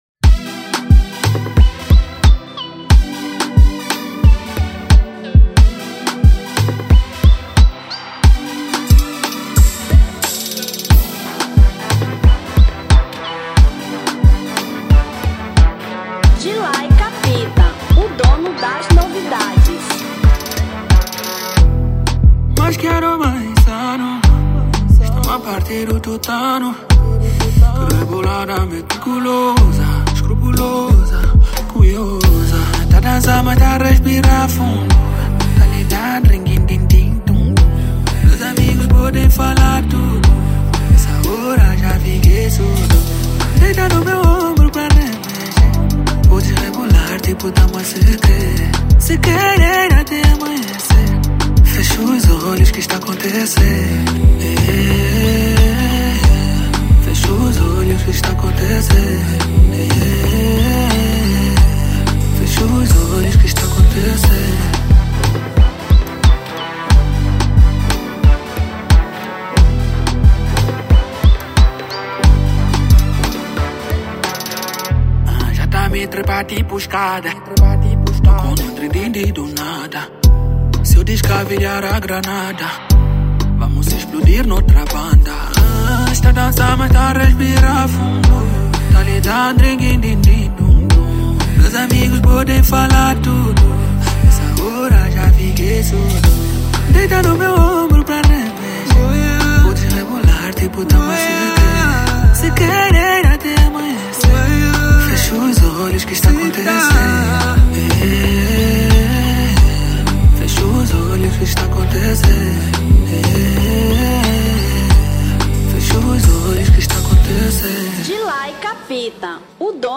Zouk 2025